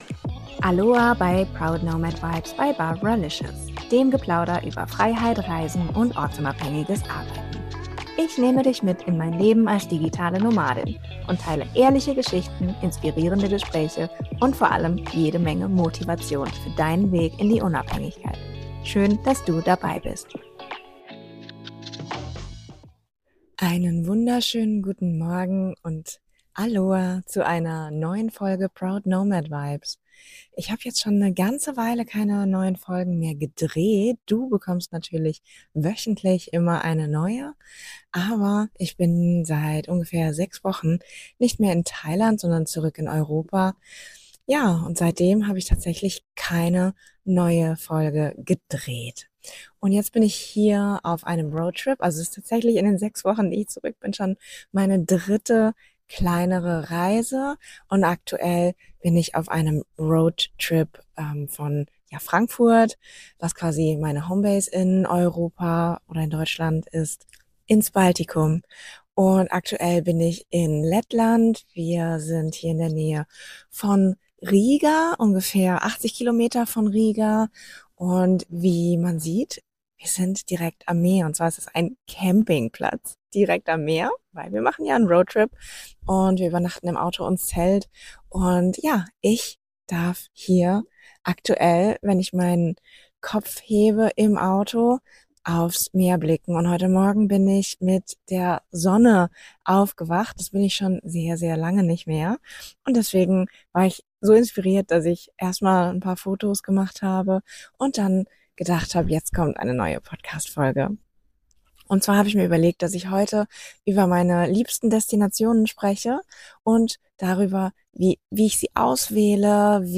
Diesmal melde ich mich aus Lettland, mitten auf meinem Roadtrip durchs Baltikum. In dieser Folge nehme ich dich mit auf meine ganz persönliche Reise und teile, welche Orte sich für mich in den letzten Jahren als echte Lieblingsdestinationen herauskristallisiert haben.